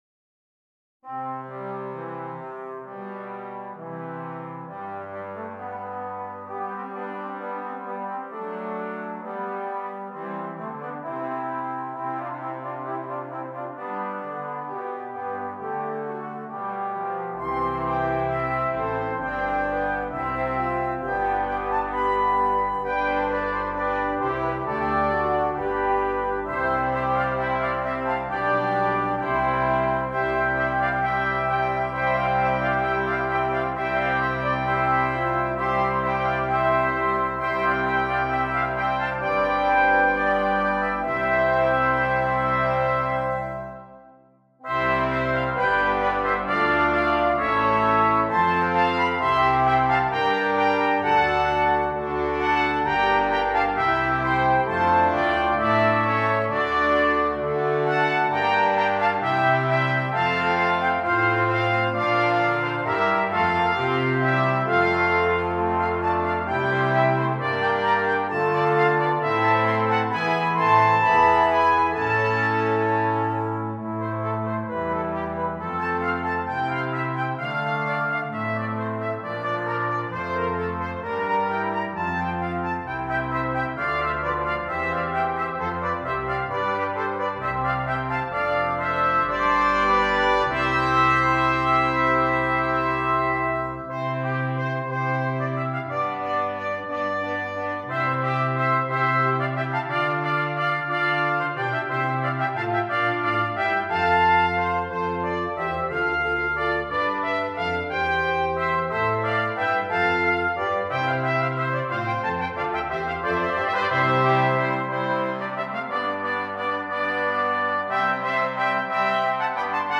Brass Choir (4.2.3.0.1)